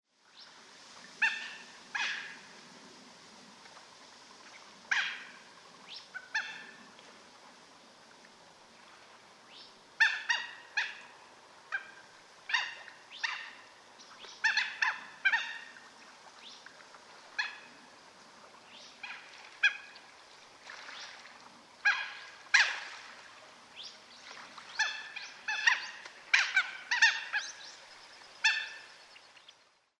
oshidori_c1.mp3